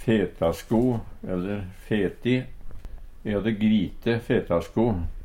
DIALEKTORD PÅ NORMERT NORSK fetasko/feti sko av reinskinn Eintal ubunde Eintal bunde Fleirtal ubunde Fleirtal bunde Eksempel på bruk E hadde gvite fetasko.